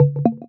Game Notification 79.wav